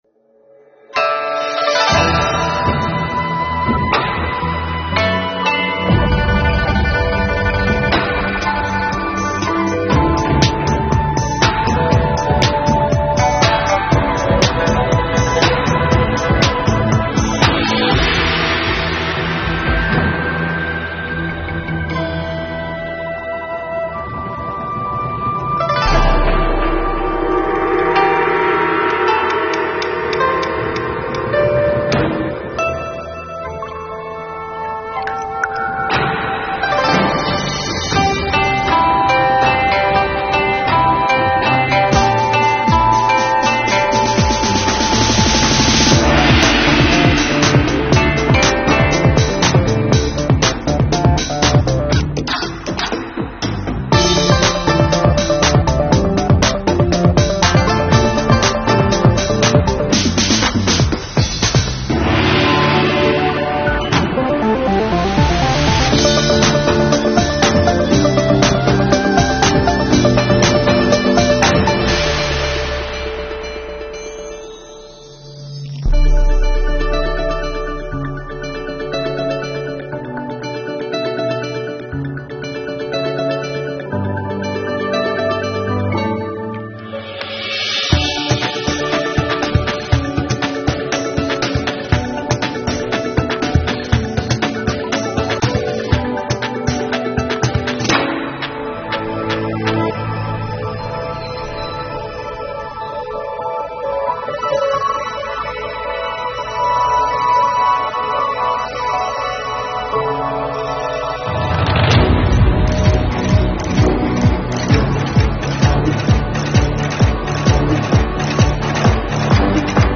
不用同期声，没有解说词，